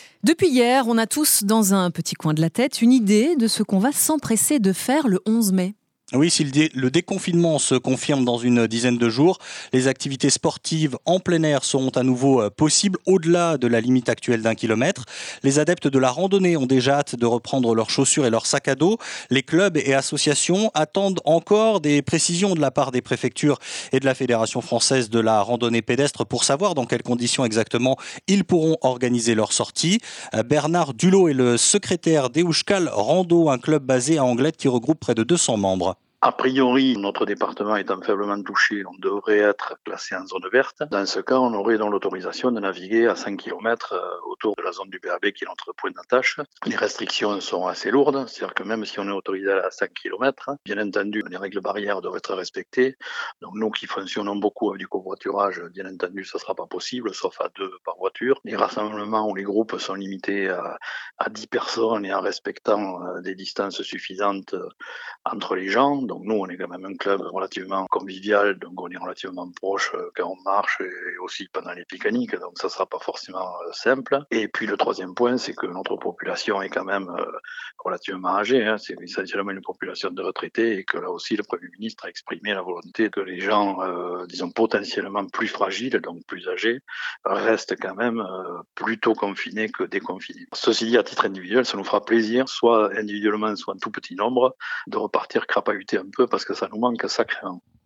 Euskal Rando sur France Bleu Pays-Basque : Interview France Bleu Pays-Basque